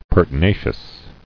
[per·ti·na·cious]